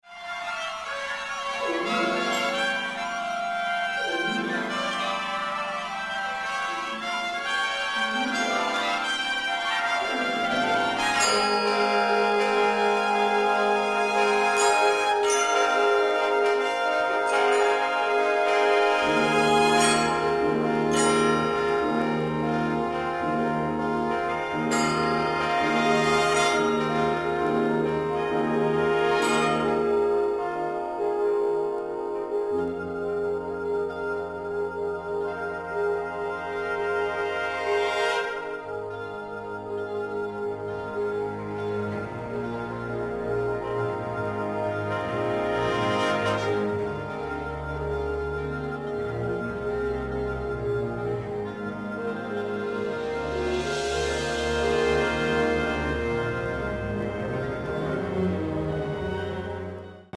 Orchestra